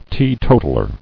[tee·to·tal·er]